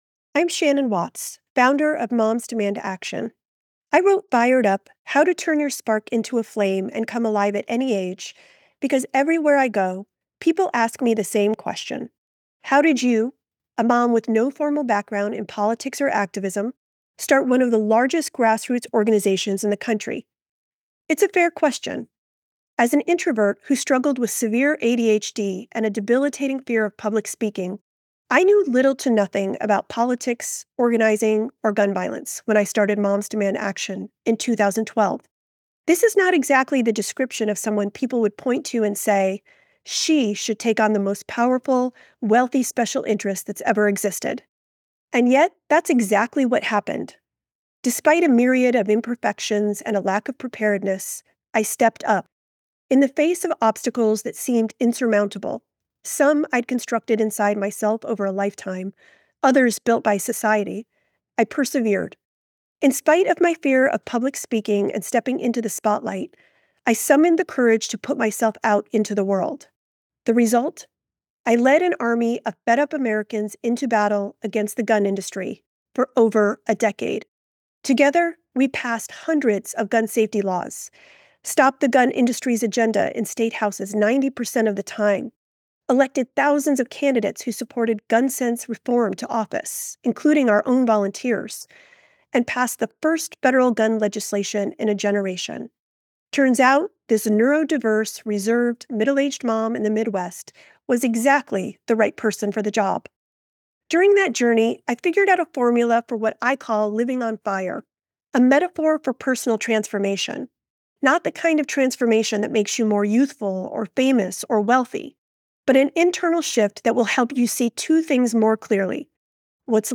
Listen to the audio version—read by Shannon herself—below, or in the Next Big Idea App.